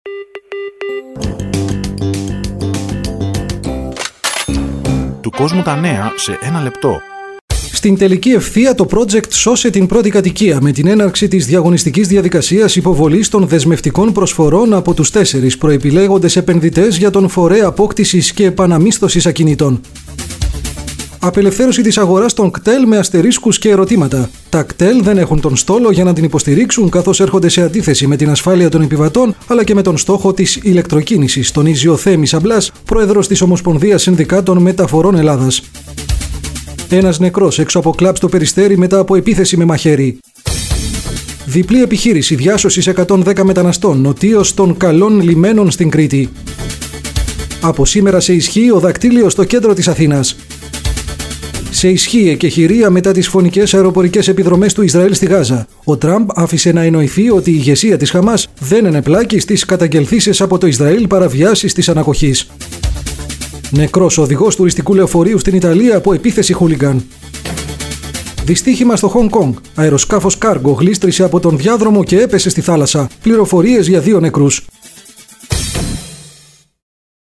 Ειδήσεις
Αμερόληπτο, σύντομο και περιγραφικό δελτίο, με περίπου 6 – 7 τίτλους ειδήσεων από την Ελλάδα και όλο τον κόσμο, μπορεί να περιέχει Πολιτικές, Πολιτιστικές, Οικονομικές, Επιστημονικές και Αθλητικές ειδήσεις, ανάλογα με την βαρύτητα της εκάστοτε είδησης.
Διαφορά των δύο δελτίων : άλλος τίτλος δελτίου, άλλη μουσική υπόκρουση, ανδρική ή γυναικεία εκφώνηση.
Ανδρική Εκφώνηση –  “Του Κόσμου τα Νέα σ’ένα λεπτό…